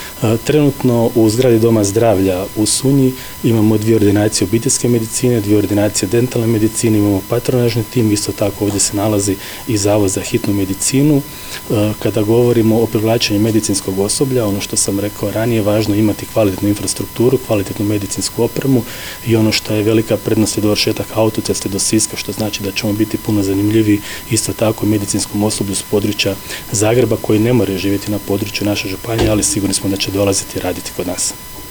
U prigodi otvorenja obnovljene zgrade župan Ivan Celjak, izrazio je zadovoljstvo i zbog korisnika usluga kao i djelatnika koji će raditi u ovom prostoru